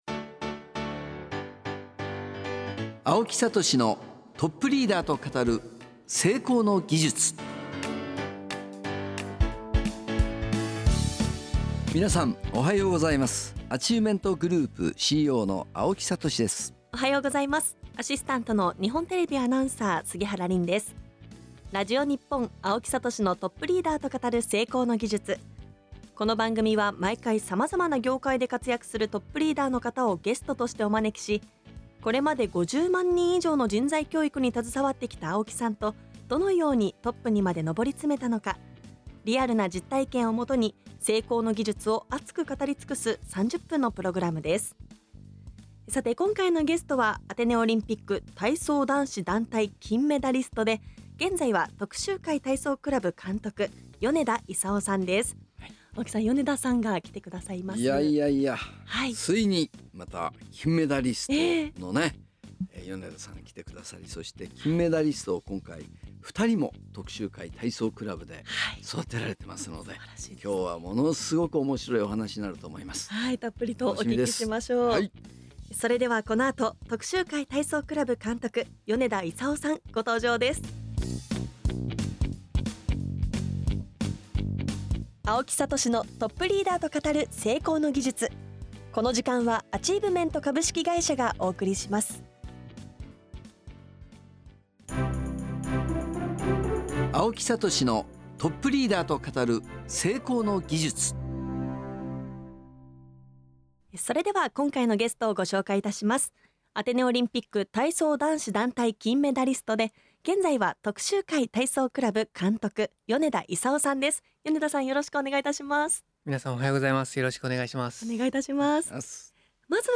今週と来週のゲストは 徳洲会体操クラブ 監督 米田功さんです！